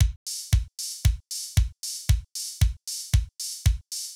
MOO Beat - Mix 8.wav